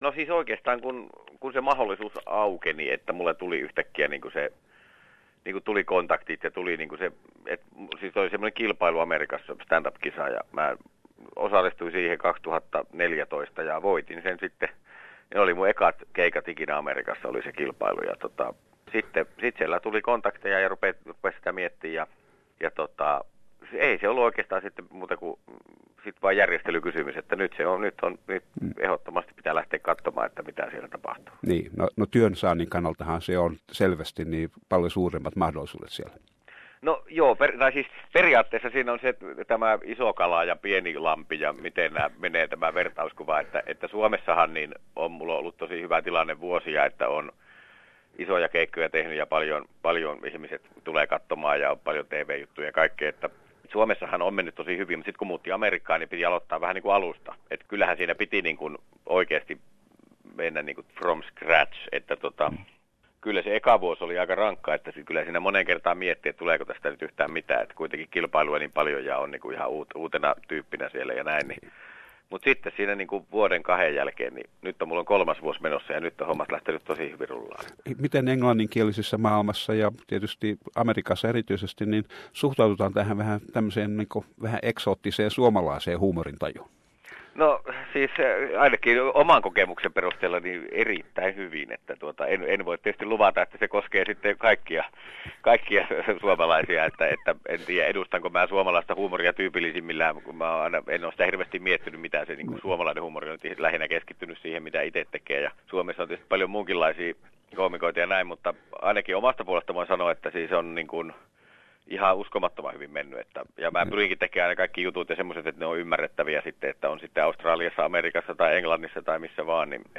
Sain yhteyden Ismoon ja juttelimme komediasta yleensä ja erityisesti Ismon suhtautumisesta komediaan.